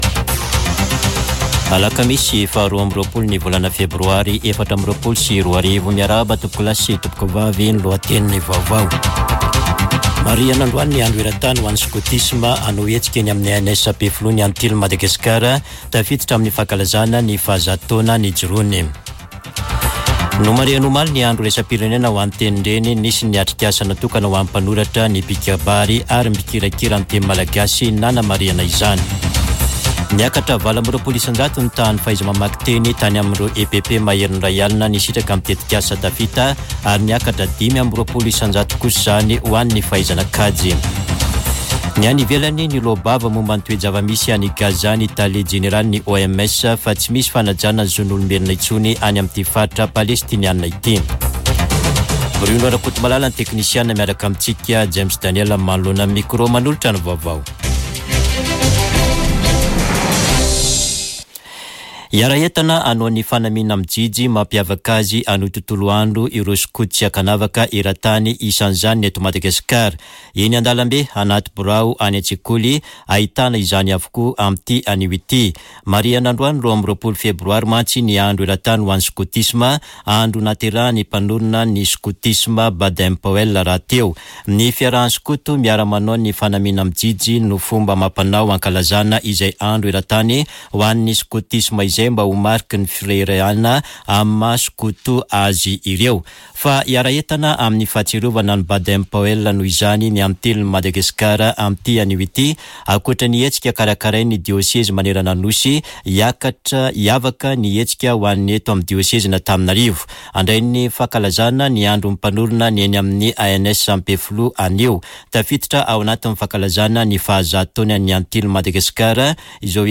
[Vaovao maraina] Alakamisy 22 febroary 2024